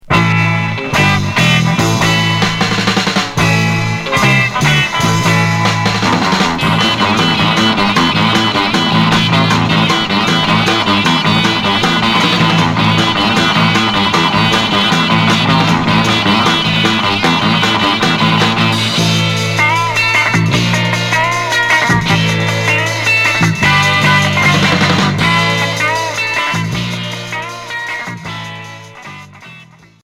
Instrumental
Twist sauvage